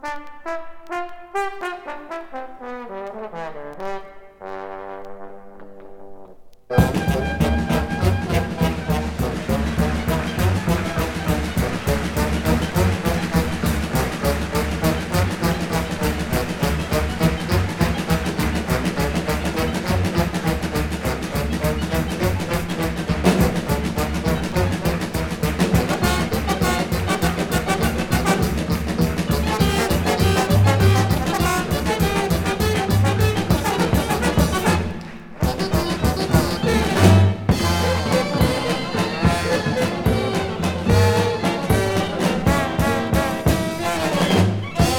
Jazz, Free Jazz　Netherlands　12inchレコード　33rpm　Stereo